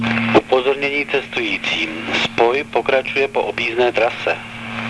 Většina nahrávek byla pořízena ve voze ev.č. 516.
H L Á Š E N Í   M I M O Ř Á D N O S T Í :
Na této stránce jsou kvalitnější zvuky, původní web obsahuje nahrávky horší zvukové kvality.